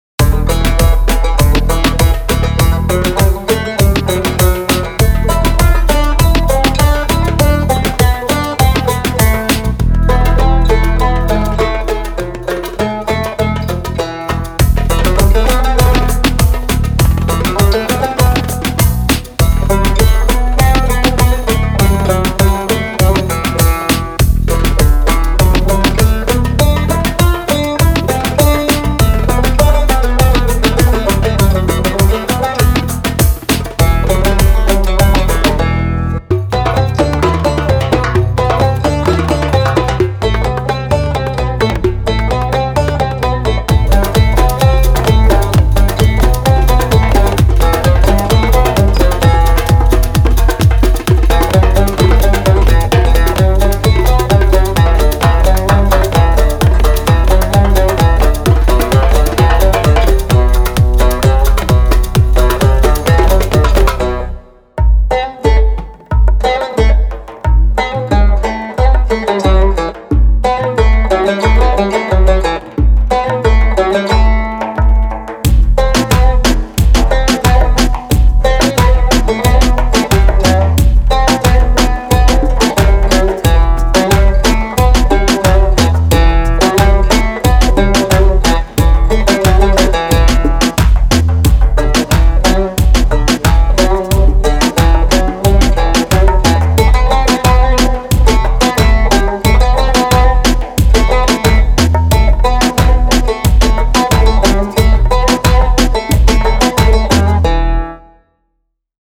Genre:Middle East
Cumbus（ジュンブシュ）- トルコのバンジョー
バンジョーのフィーリングと中東音楽の魂を融合させた、トルコの弦楽器です。
デモで使用されている楽器（Cumbusを除く）は、あくまで使用例です。
Live Performed & Played